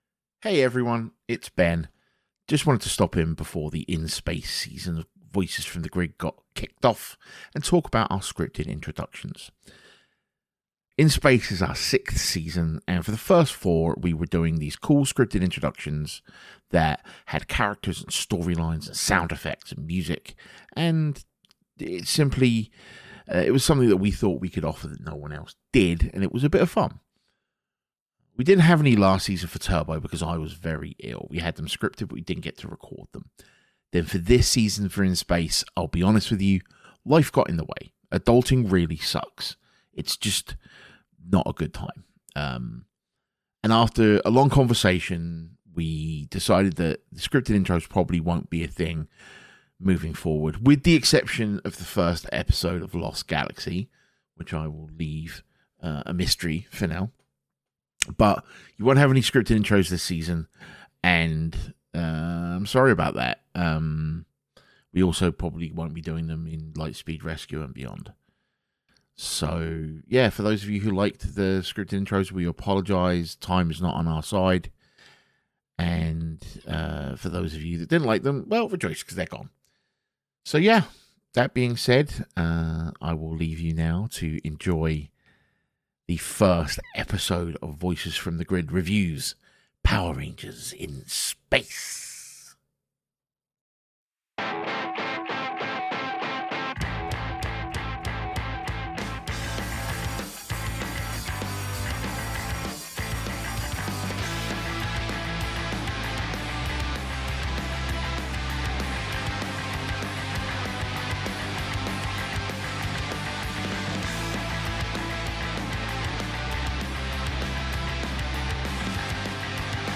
The Show Where 4 Adults Takes a Kids Show Too Seriously!